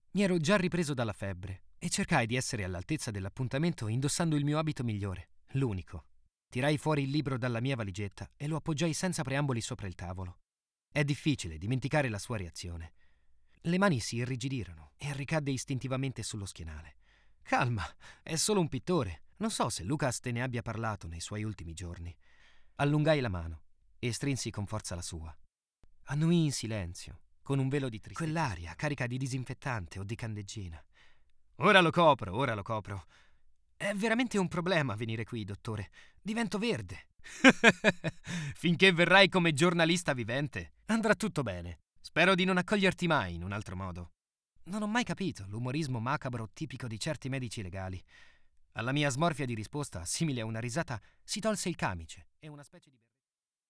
Ho una voce calda e giovane, utilizzabile in ogni tipo di progetto.
Sprechprobe: Sonstiges (Muttersprache):